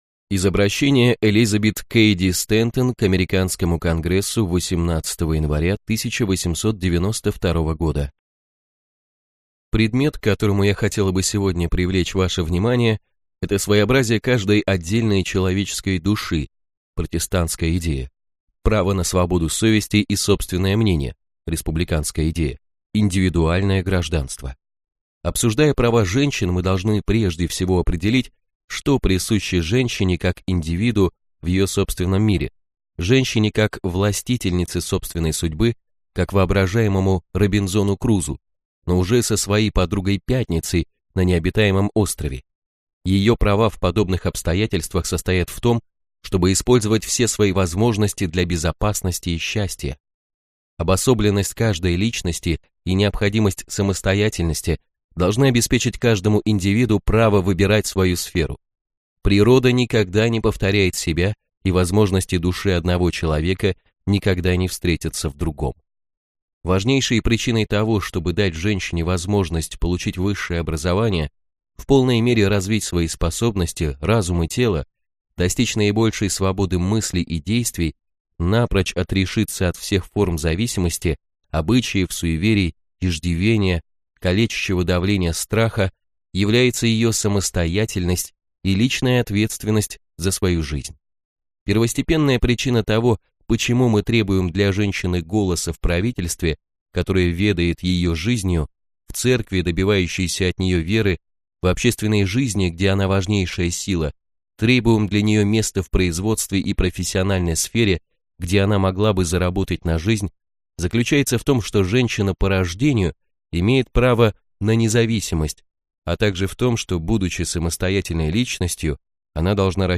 Аудиокнига Наука быть великим | Библиотека аудиокниг
Прослушать и бесплатно скачать фрагмент аудиокниги